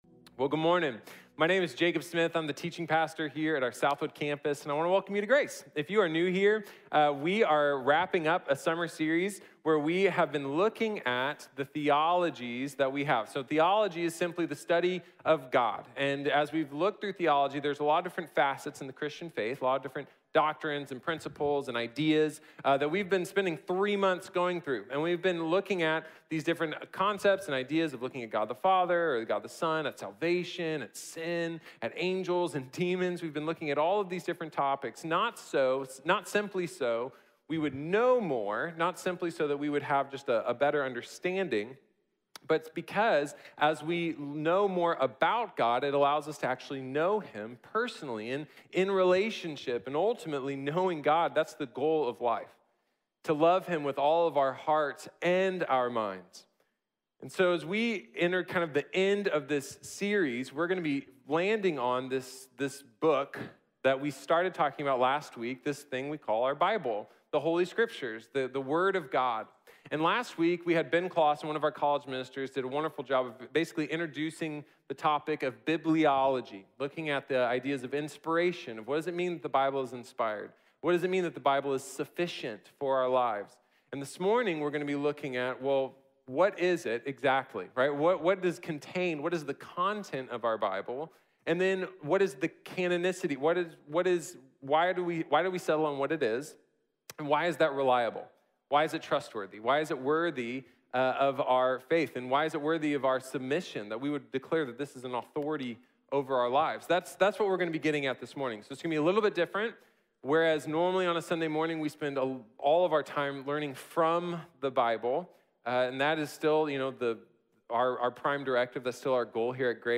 Bibliología | Sermón | Iglesia Bíblica de la Gracia